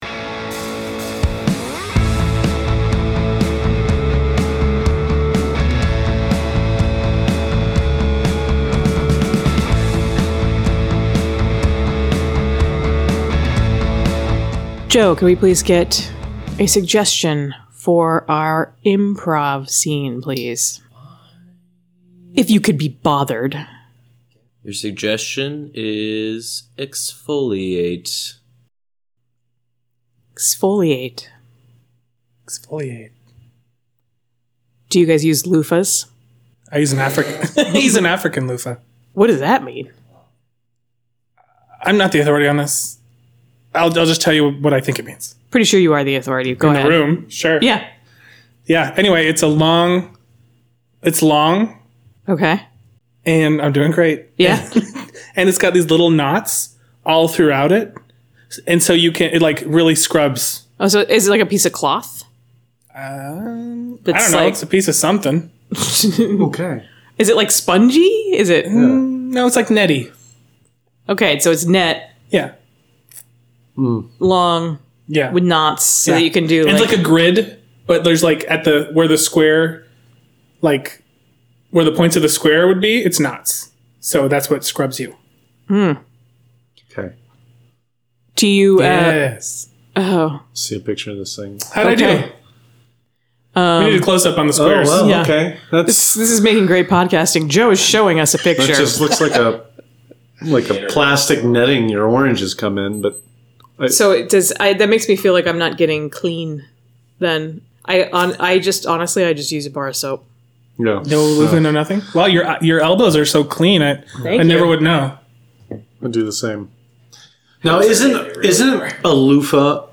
Bonus IMPROV - Let Gunther Put the Blinders On You